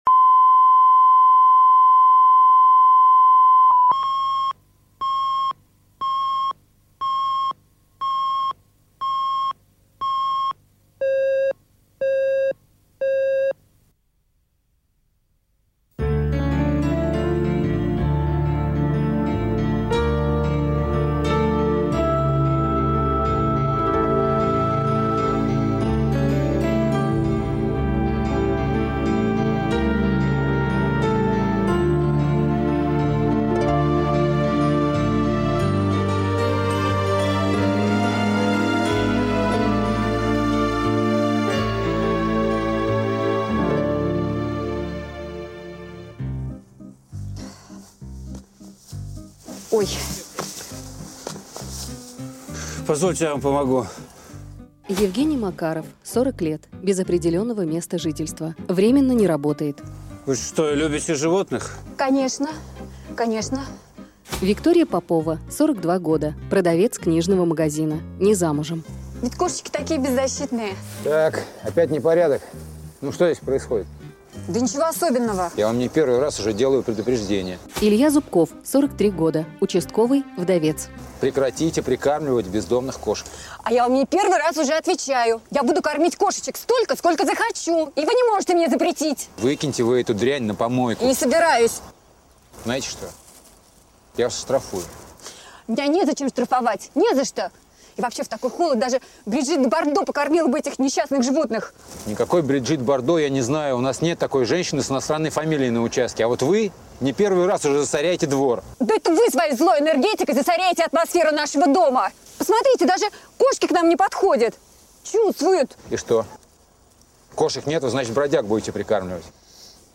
Аудиокнига Любимый бродяга | Библиотека аудиокниг